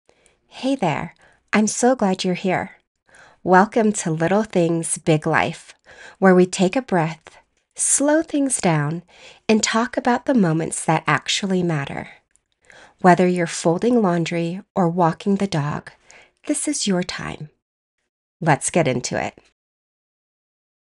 Podcast Intro - Conversational & Warm.mp3
Middle Aged